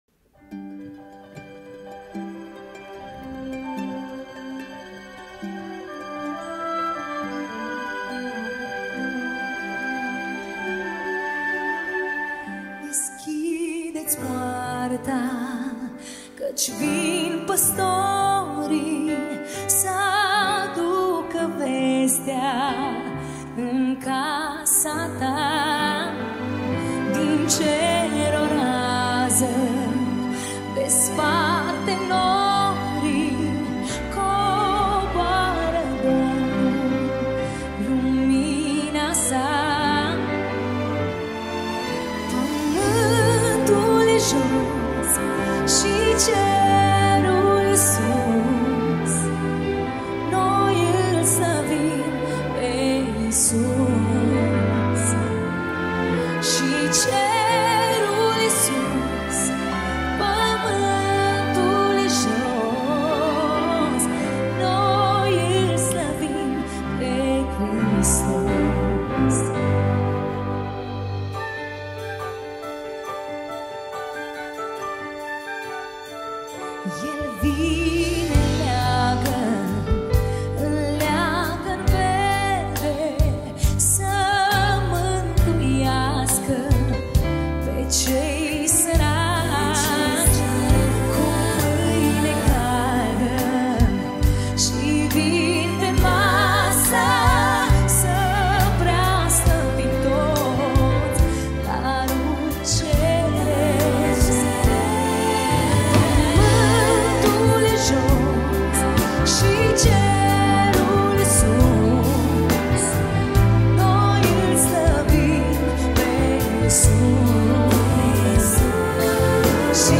Categoria: Colinde Craciun